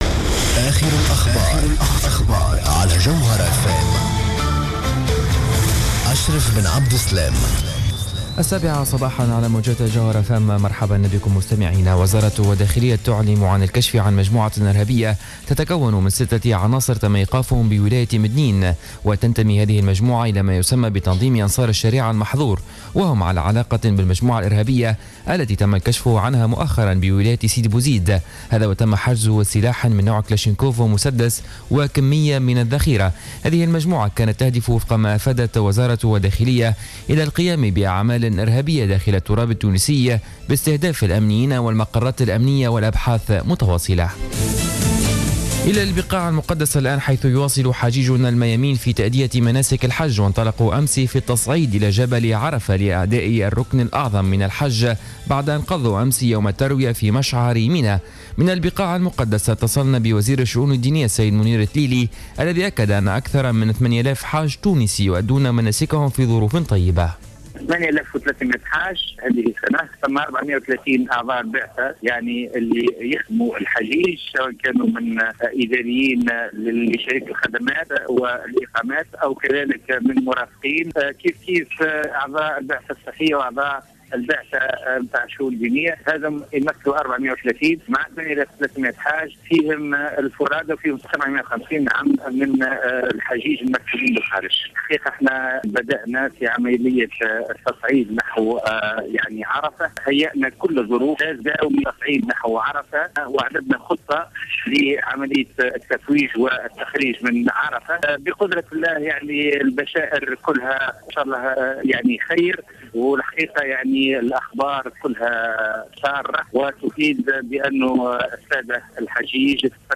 نشرة أخبار السابعة صباحاً ليوم الجمعة 03-10-14